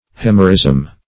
Search Result for " euhemerism" : The Collaborative International Dictionary of English v.0.48: Euhemerism \Eu*hem"er*ism\n. [L. Euhemerus, Gr.